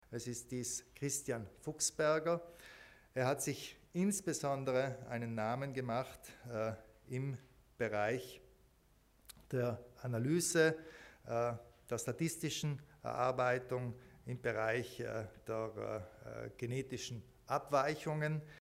Landeshauptmann Kompatscher begründet die Verleihung des Forschungspreises des Landes Südtirol